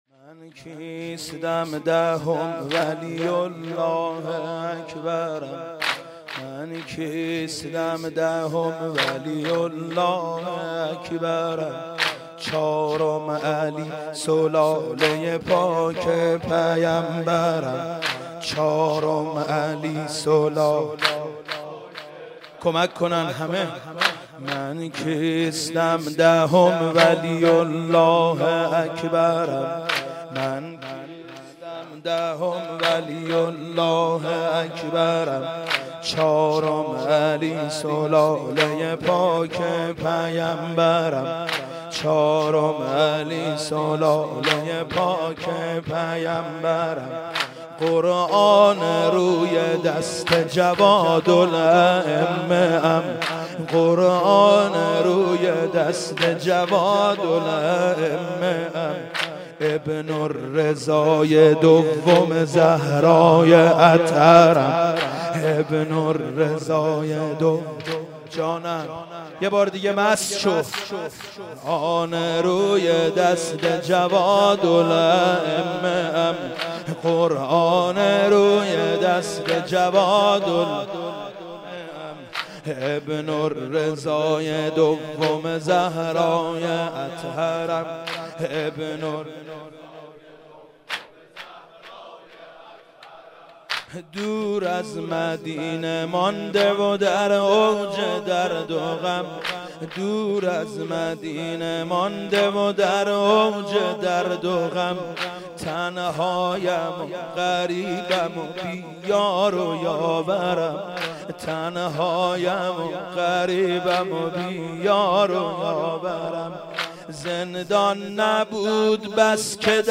مداحی
بمناسبت شهادت امام هادی (ع)